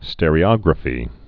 (stĕrē-ŏgrə-fē, stîr-)